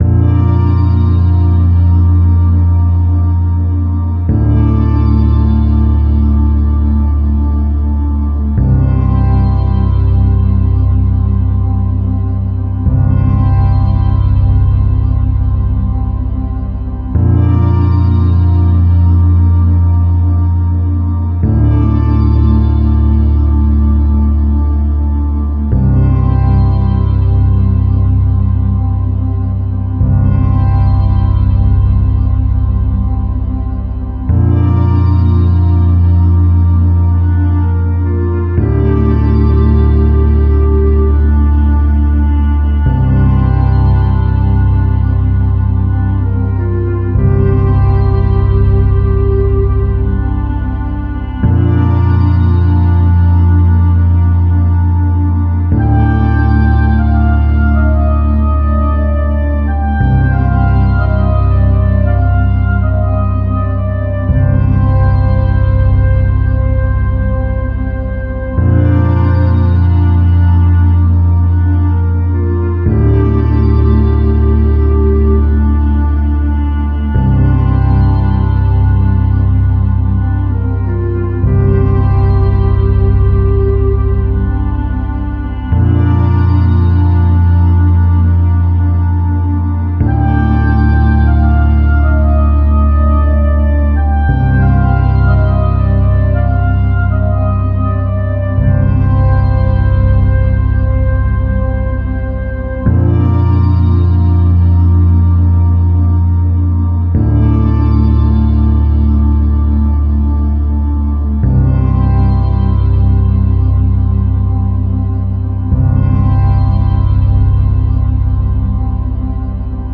Film Score
Orchestral 2:05 Film Score